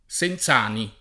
[ S en Z# ni ]